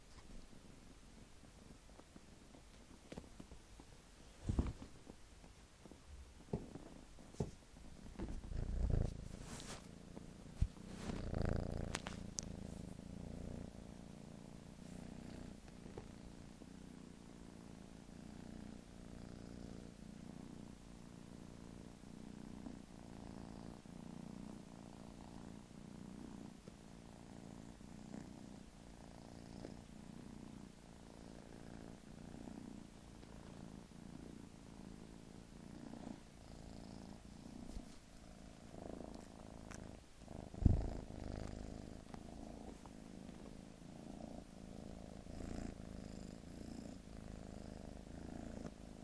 Purr machine
77722-purr-machine.mp3